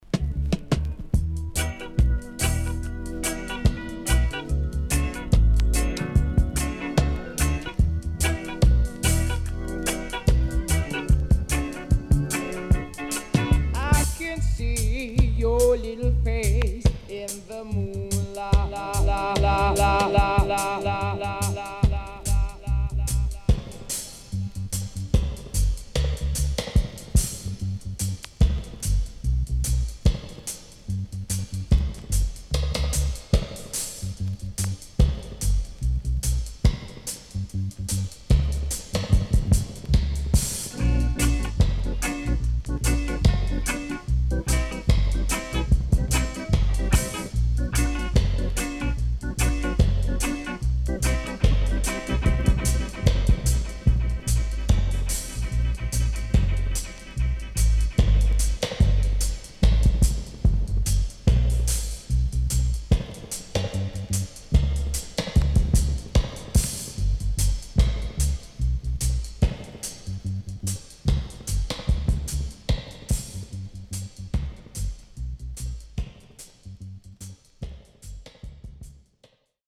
HOME > REGGAE / ROOTS
Nice Vocal & Dubwise
SIDE A:所々チリノイズがあり、少しプチノイズ入ります。